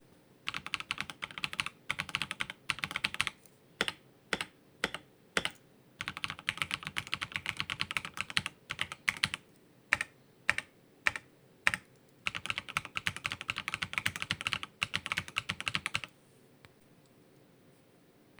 Im lặng, các switch này không có hiệu ứng clicky và chỉ có phần cuối hành trình (điểm dừng của switch) mới phát ra tiếng ồn.
Nó cho phép gõ thoải mái hơn, giảm rung và tiếng ồn, cũng như cảm giác đàn hồi tốt hơn. Đây là tiếng ồn được tạo ra khi gõ:
Hiệu ứng tạo ra được kiểm soát. Chỉ những phím lớn hơn (thanh cách, phím Enter) là ồn hơn.